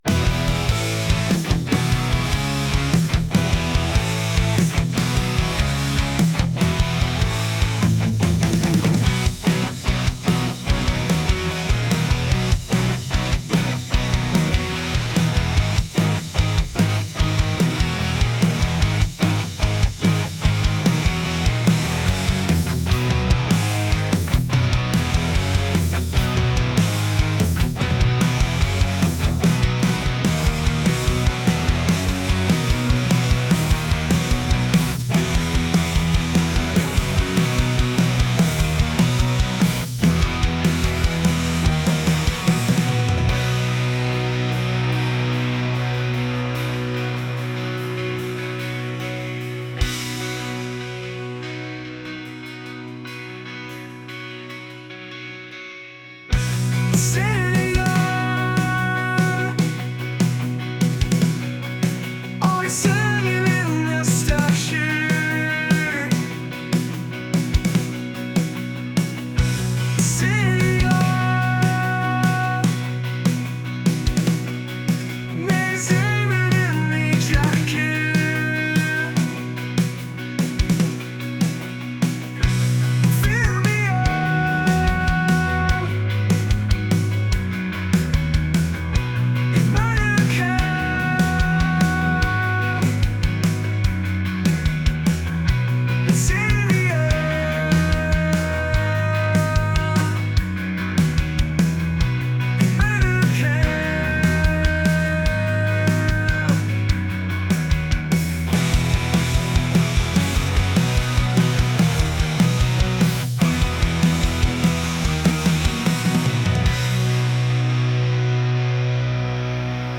energetic | pop | punk